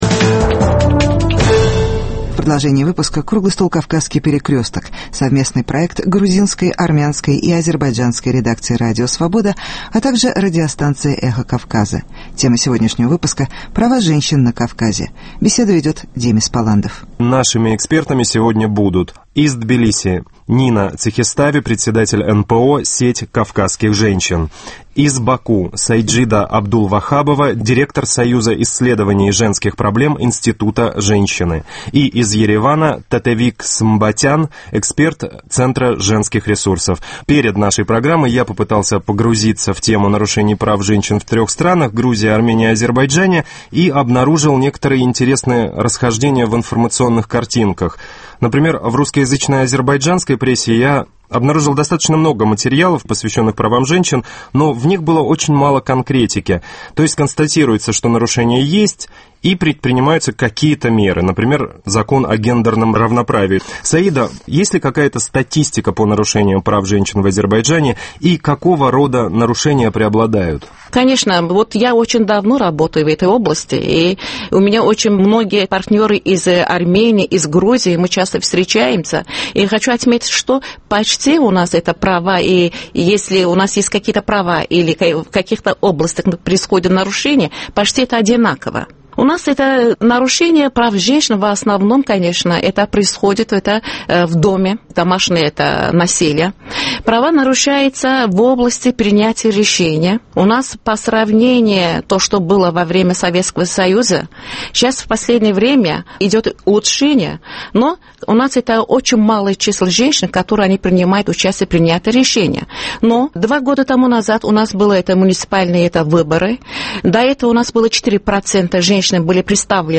Круглый стол «Кавказский перекресток» - о роли женщин на Кавказе